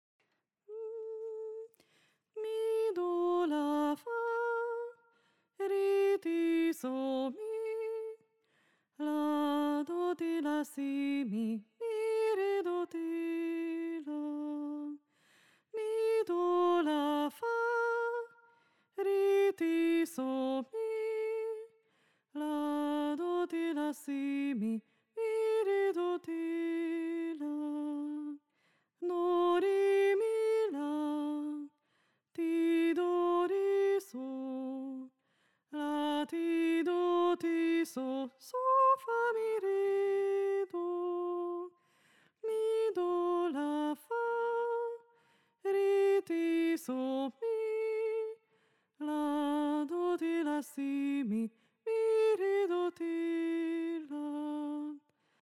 Régi barokk táncok és új stílusú magyar népdalok, táncdallamok